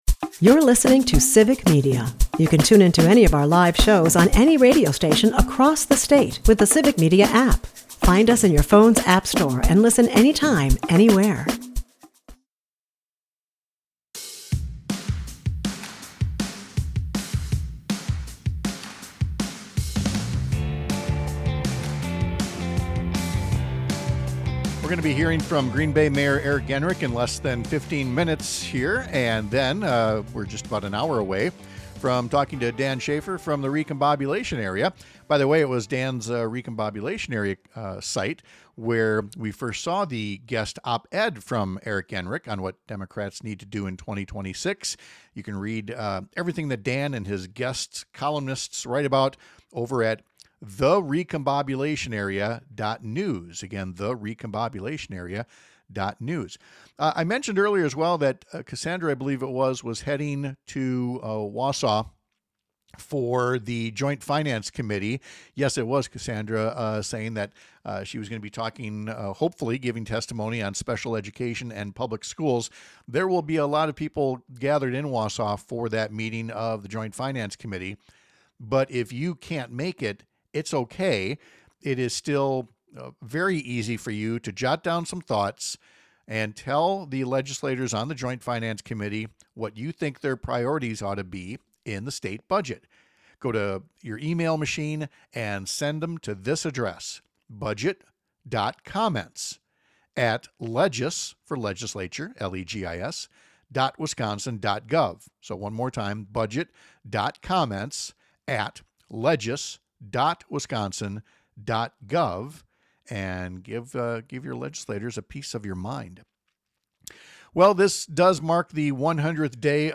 Mayor Genrich joined Pat Kreitlow to talk about the essay and engage in some back-and-forth about where Democrats can put more emphasis on getting results rather than the process of seeking results. Pat also gives his thoughts on Donald Trump's first 100 days and welcomes guest WI State Senators Kelda Roys and Jeff Smith as well as Assembly Member Jodi Emerson. Mornings with Pat Kreitlow airs on several stations across the Civic Media radio network, Monday through Friday from 6-9 am.